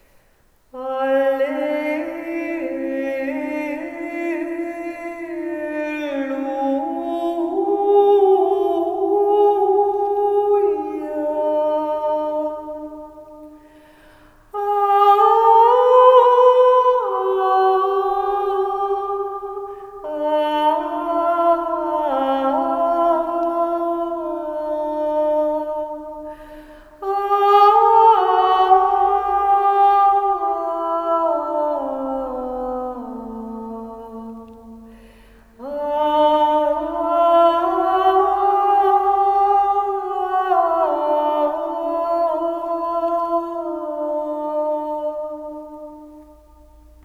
Dans le cadre des ateliers de chant sacré, voici quelques illustrations sonores :- Antienne Exultet spiritus meus- Alleluia Laetabitur- Kyrie Fons bonitatis- Gloria laus et honor